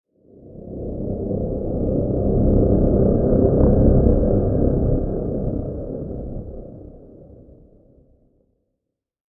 Dark-dramatic-cinematic-drone-sound-effect.mp3